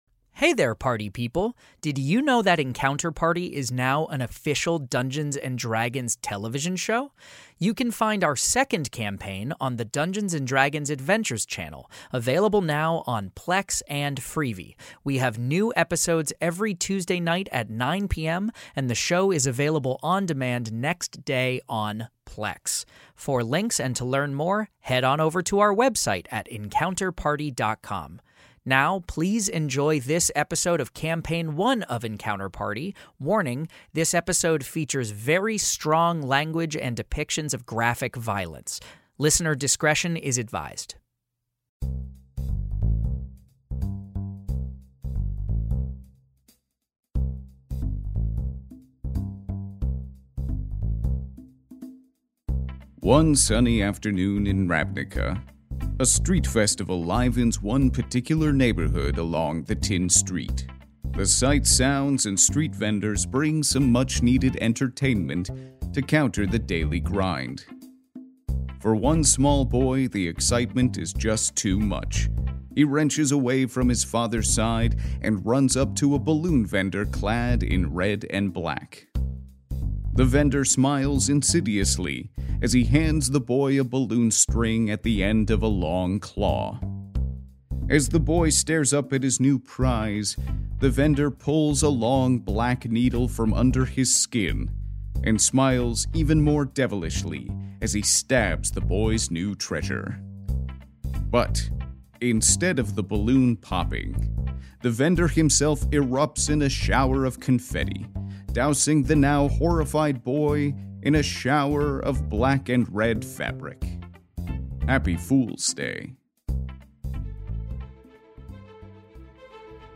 Fantasy Mystery Audio Adventure